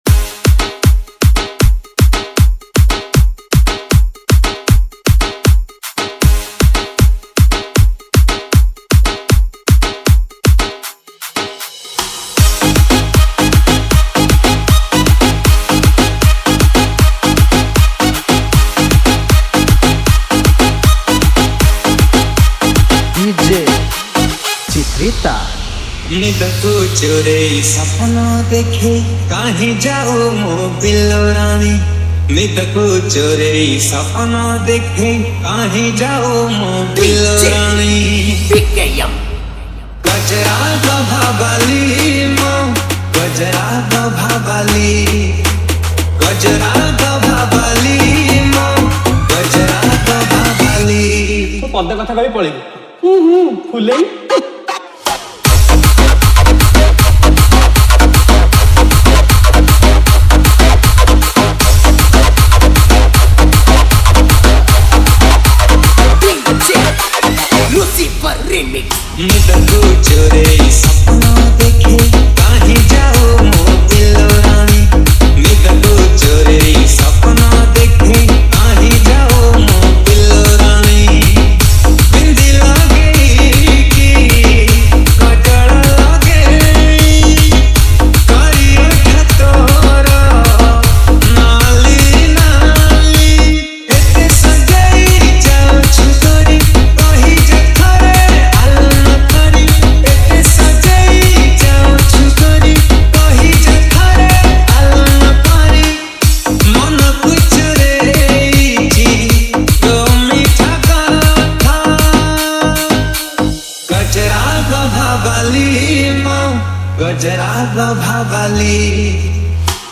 ODIA ROMANTIC DJ REMIX